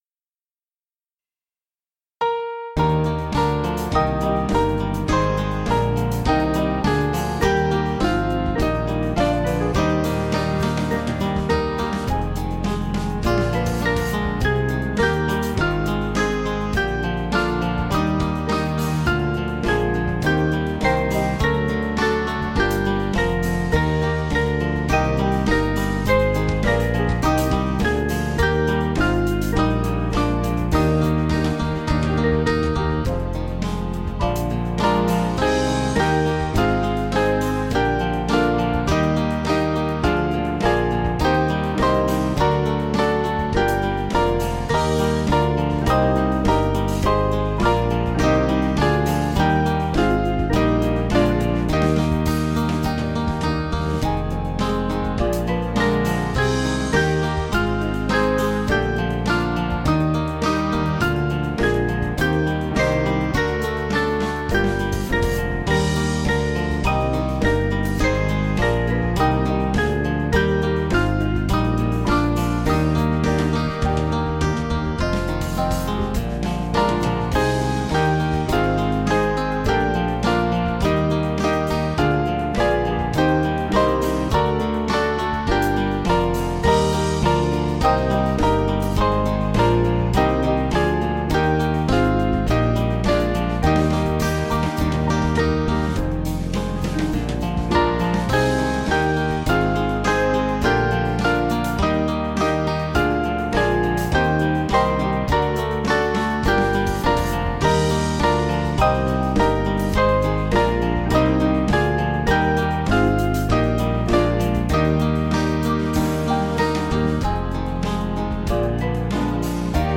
Small Band
(CM)   5/Eb 486.8kb